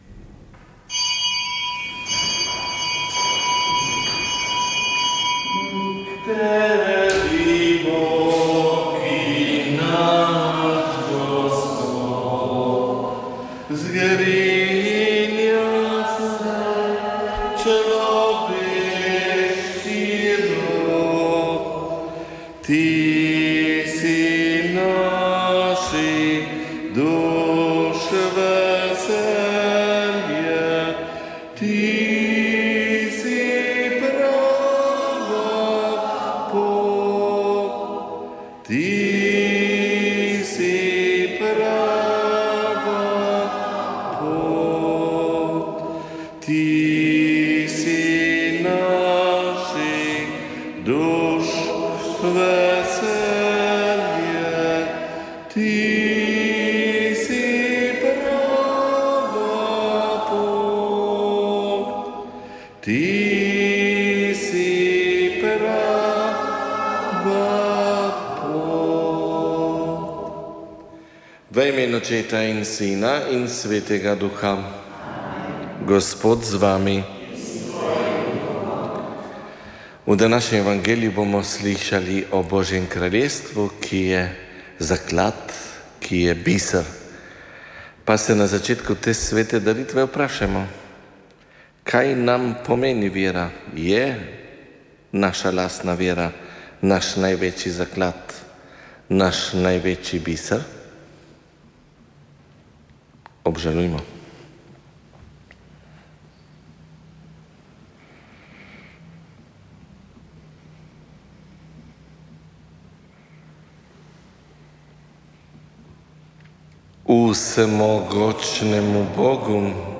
Sveta maša
Sv. maša iz stolne cerkve sv. Janeza Krstnika v Mariboru 18. 9.